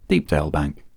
Hi, here are some samples of the final ‘d’, ‘t’ etc. sounds disappearing or becoming very faint after using de-clicker.